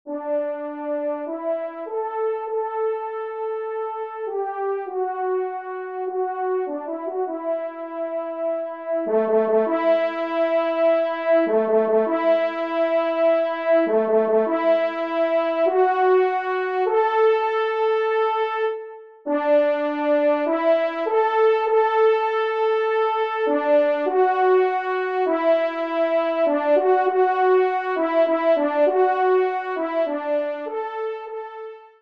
Genre :  Divertissement pour Trompes ou Cors & Orgue
Pupitre 1° Trompe